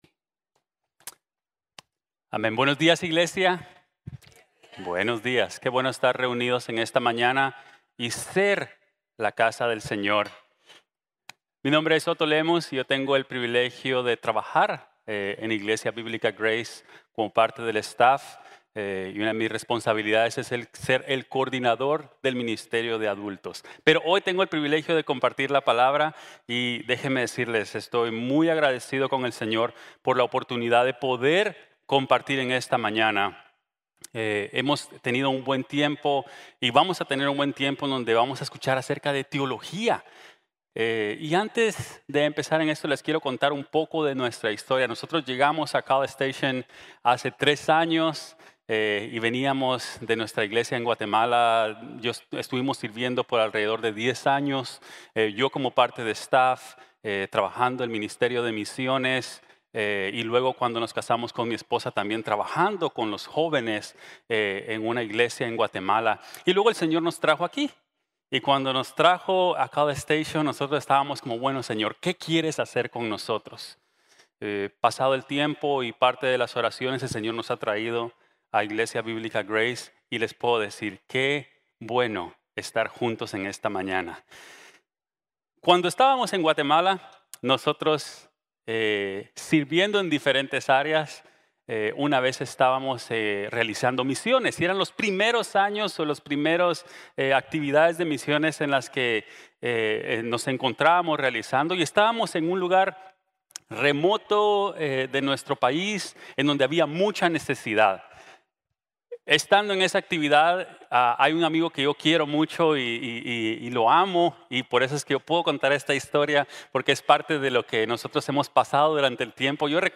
Bibliología | Sermon | Grace Bible Church